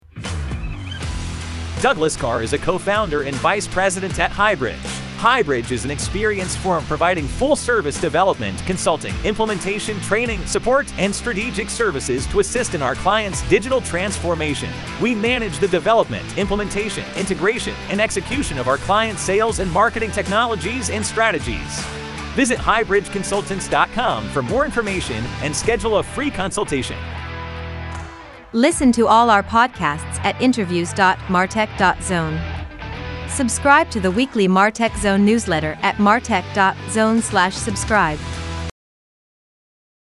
Murf: A Voice-To-Text Studio With Voices Powered By Artificial Intelligence (AI)
Within an hour I was able to record them, tweak the timing, and add background music.
I also noticed when I modified the emphasis, pitch, or speed, the voice didn’t sound as natural.
Podcast Sponsor and Outro